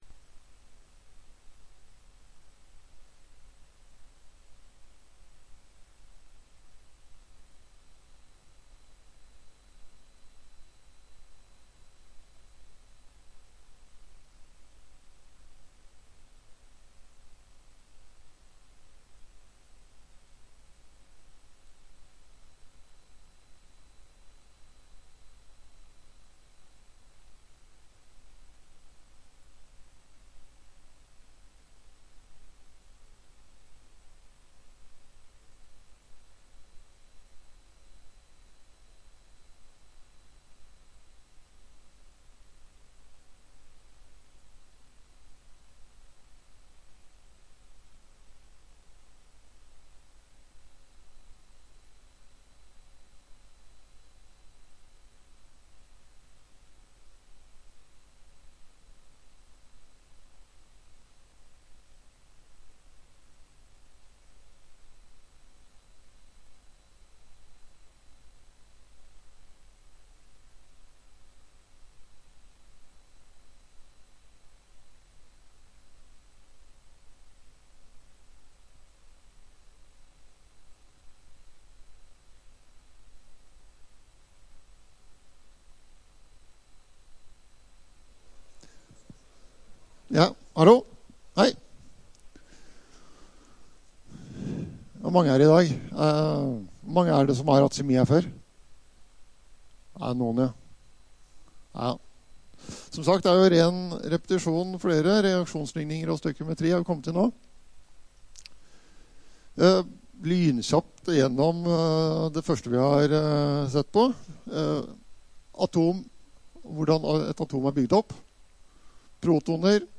Kjemiforelesning 3
Rom: Store Eureka, 2/3 Eureka